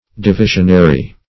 Divisionary \Di*vi"sion*a*ry\, a.